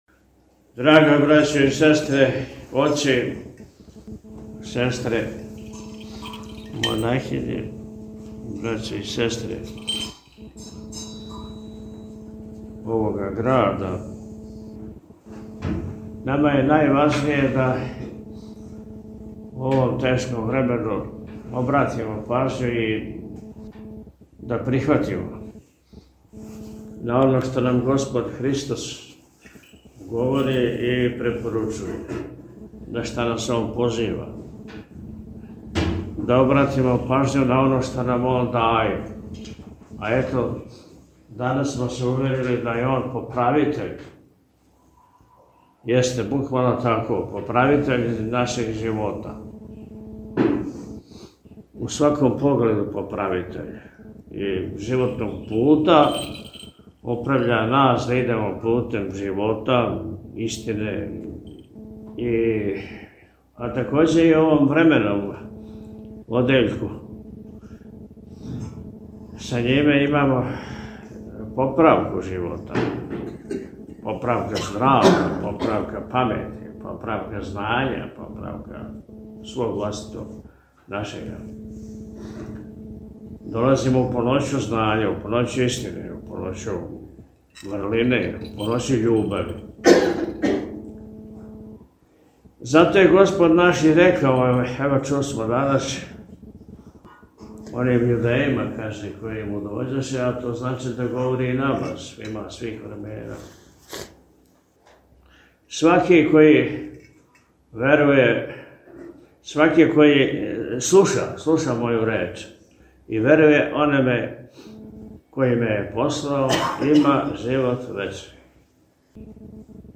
На Задушнице, 22. фебруара 2025. године, Његово Високопреосвештенство Архиепископ и Митрополит милешевски г. Атанасије служио је Свету архијерејску Литургију у параклису Свете Касијане манастира Милешеве.